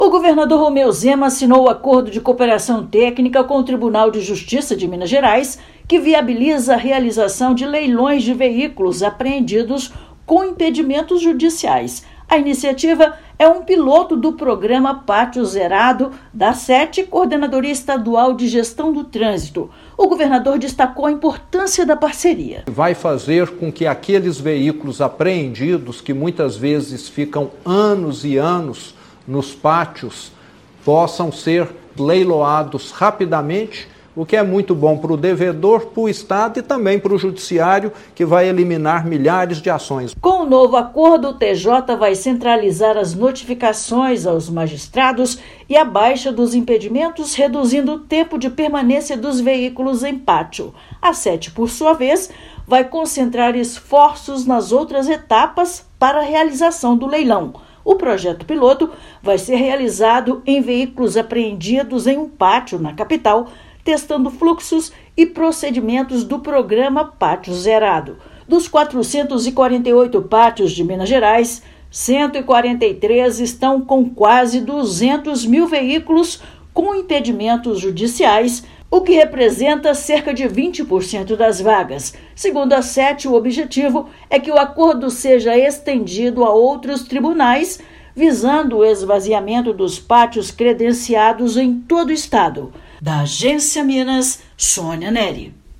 [RÁDIO] Governo de Minas firma acordo com o TJMG para agilizar leilão de veículos com impedimentos judiciais
Parceria integra o projeto Pátio Zerado e busca realizar leilões de milhares de veículos retidos em pátios credenciados. Ouça matéria de rádio.